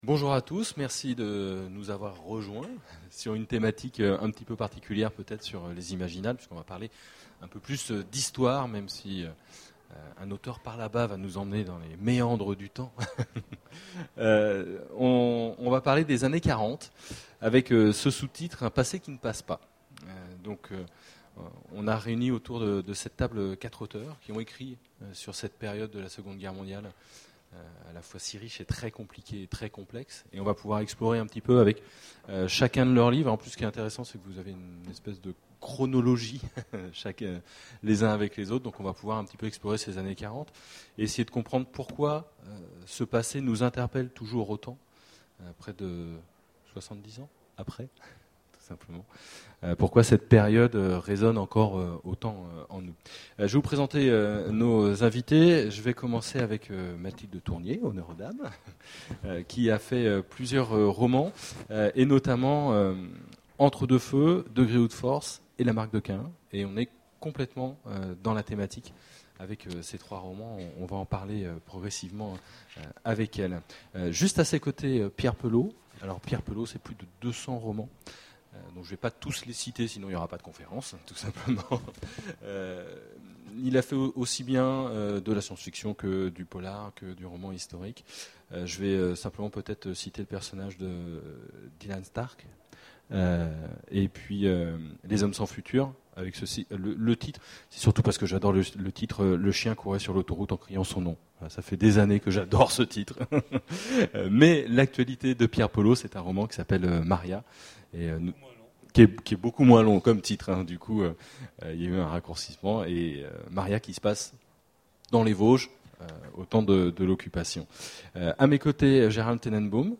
Imaginales 2011 : Conférence Les années 40, un passé qui ne passe pas